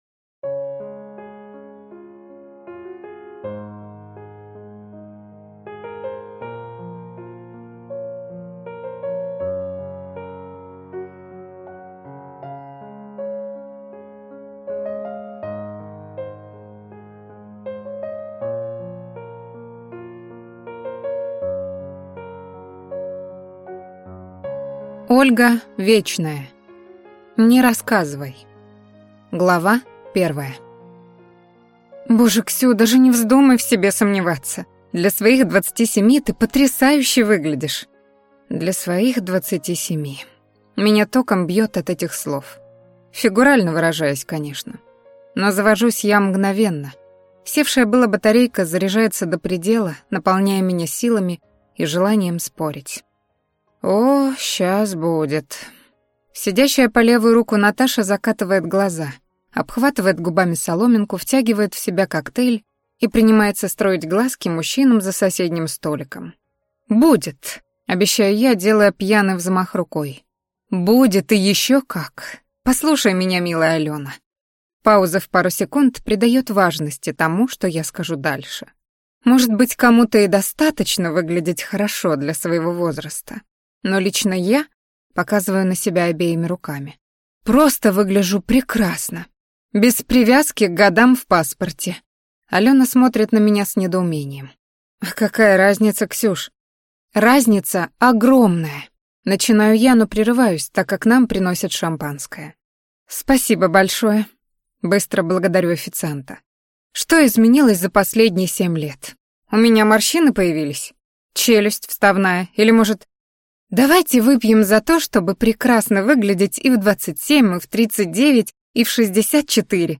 Аудиокнига Не рассказывай | Библиотека аудиокниг